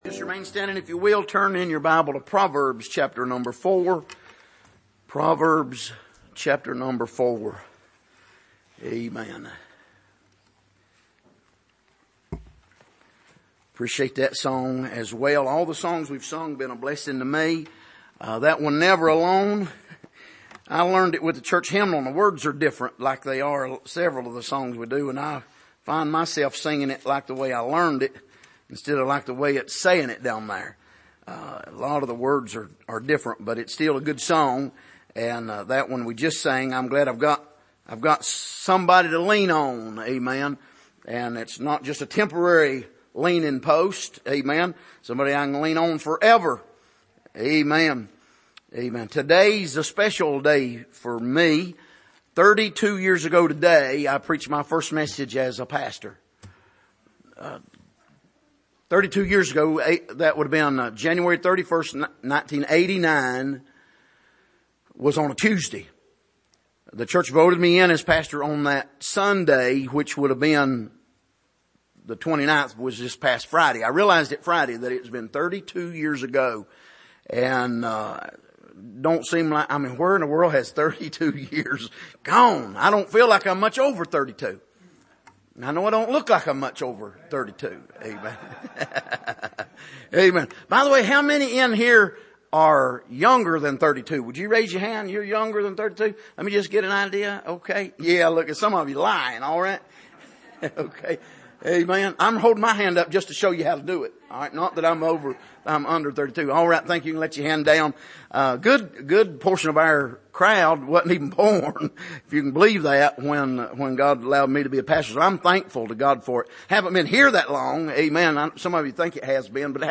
Passage: Proverbs 4:11-14 Service: Sunday Evening